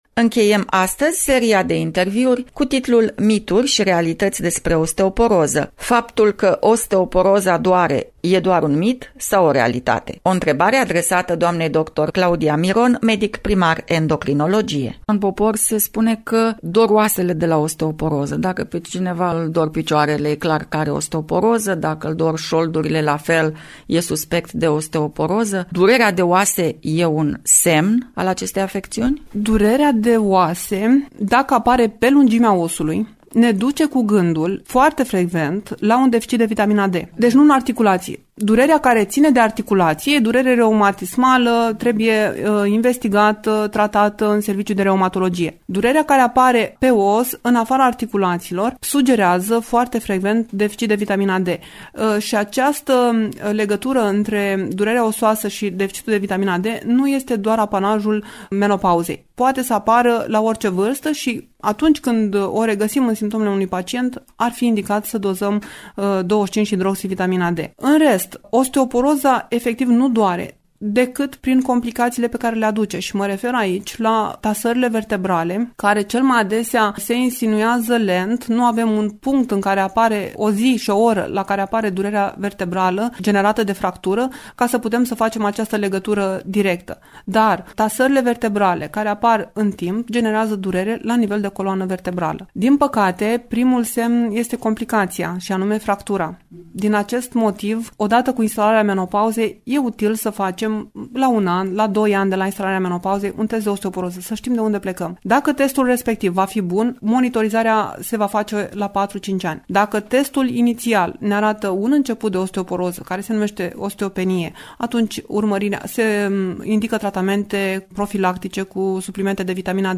Astăzi, ultima parte a interviurilor consacrate acestei afectiuni frecvente :